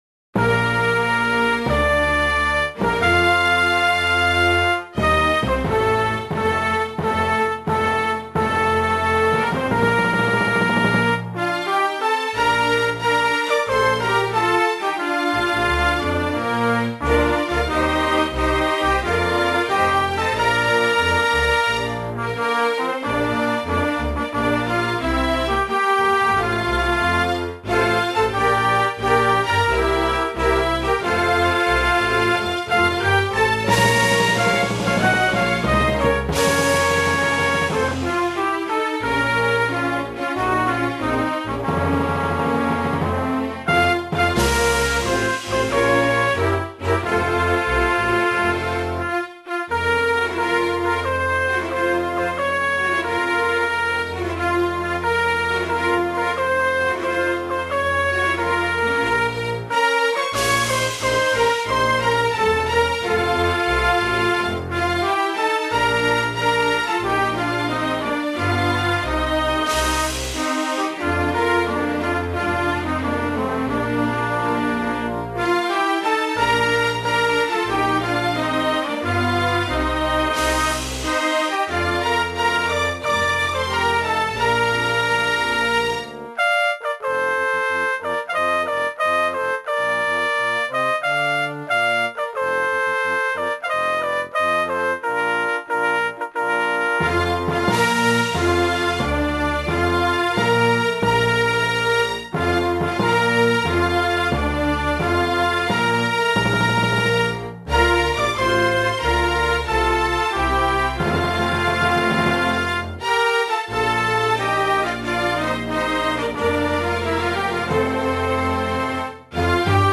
2 channels
hym_argentinia.mp3